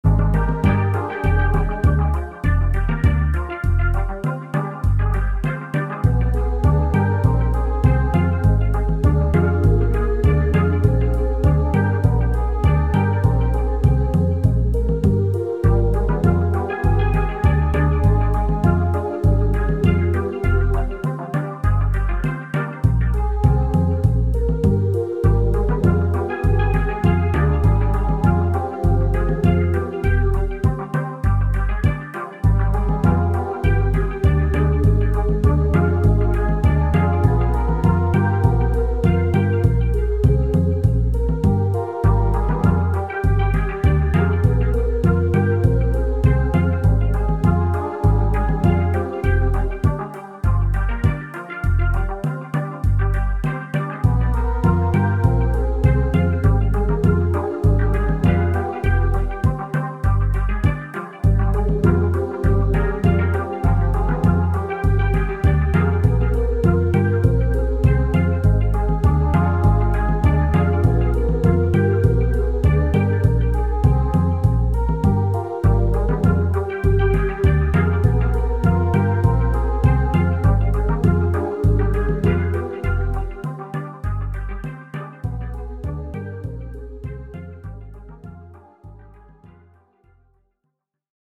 Dans la pratique j'en utilise actuellement 5 pour, les percussions, la basse et 3 instruments pouvant être rythmiques ou mélodiques.
Voici 3 ambiances musicales pour illustrer les capacités de la Société Henon.